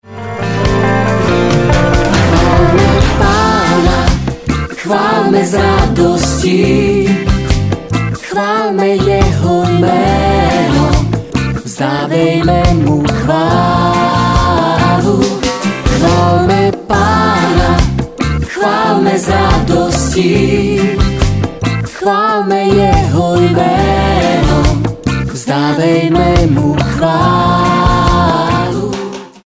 Sborový zpěv: